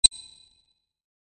43_coinSound.mp3